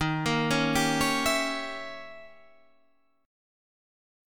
Eb7b9 chord